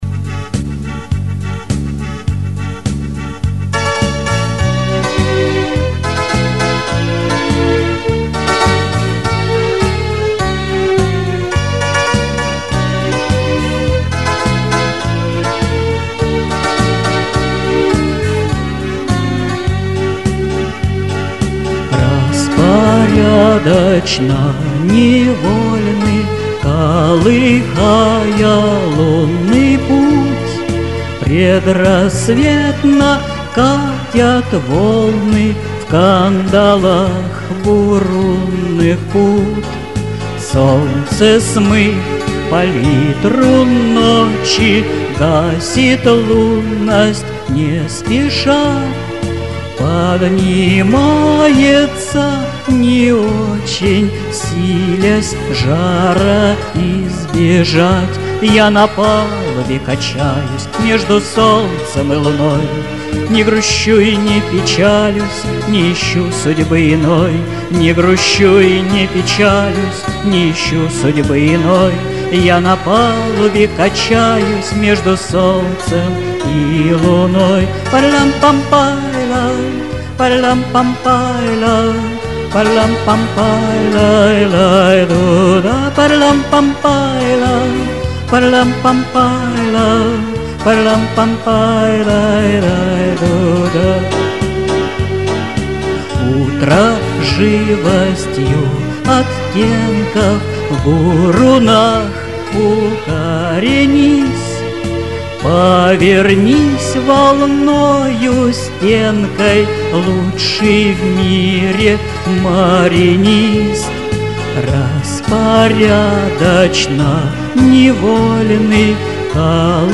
Песни